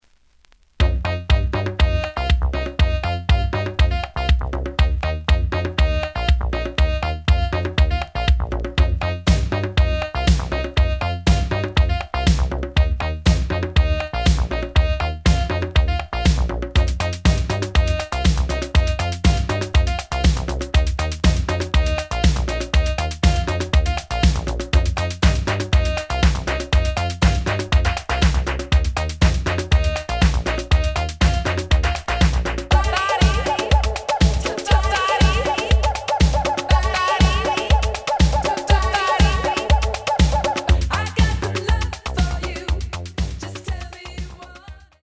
The audio sample is recorded from the actual item.
●Genre: House / Techno